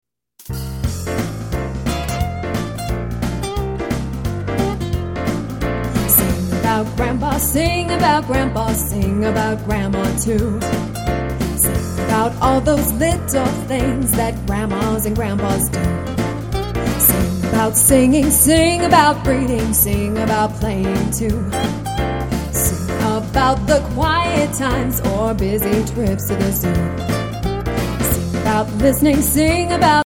song clip